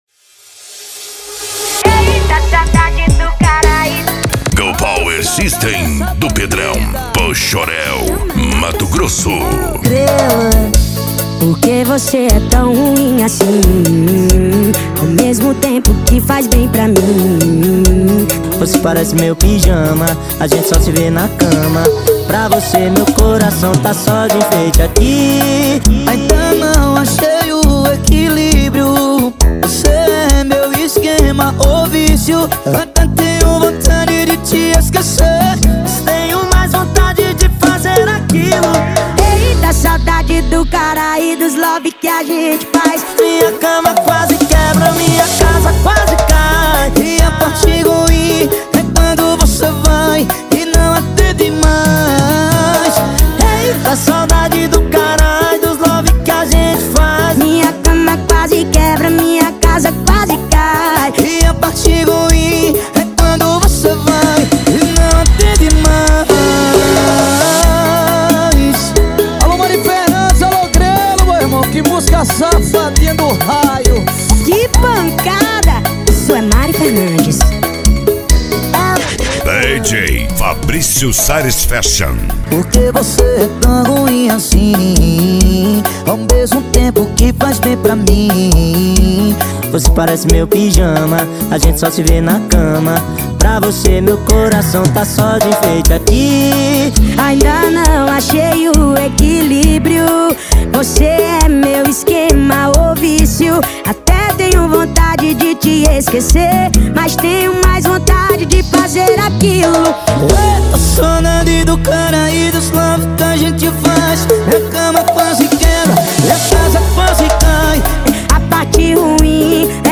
Funk
SERTANEJO